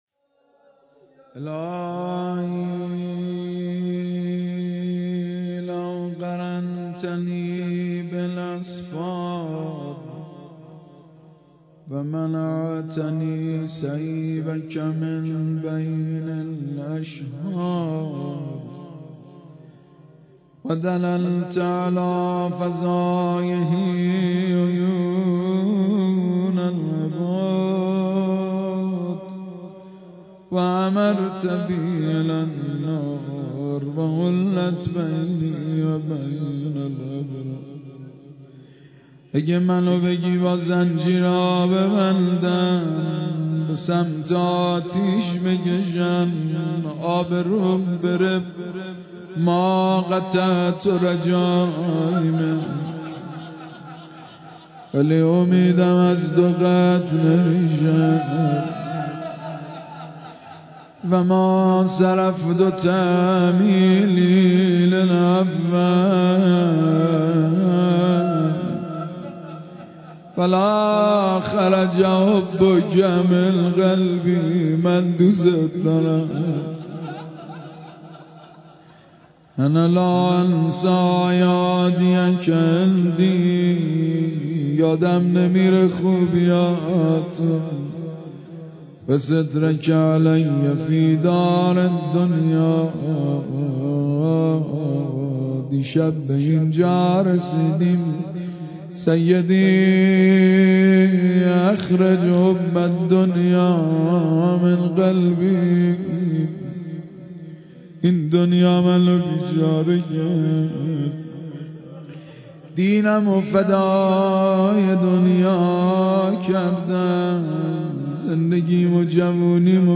مداحی
در مسجد کربلا برگزار گردید.